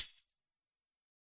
Tag: 多次采样 塔姆伯林 样品 手鼓 编辑